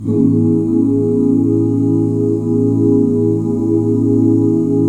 AMAJ7 OOO.wav